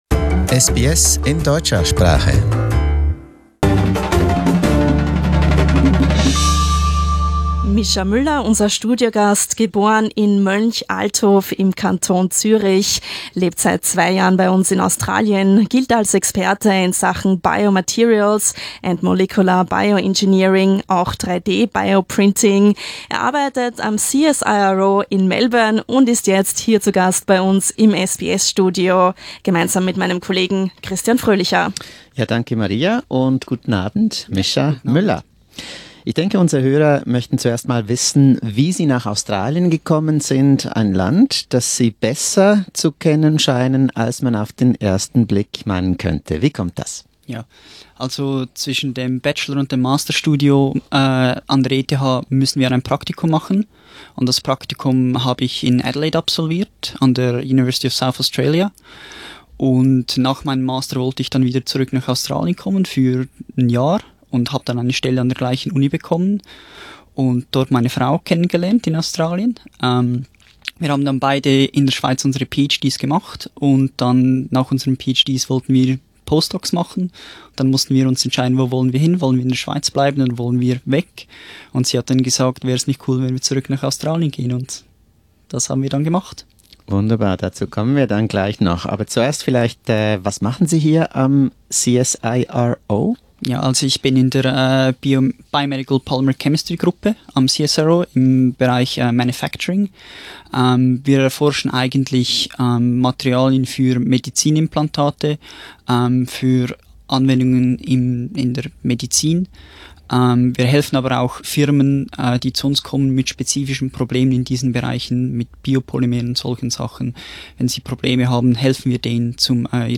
Live-Interview bei SBS Radio am Federation Square, Melbourne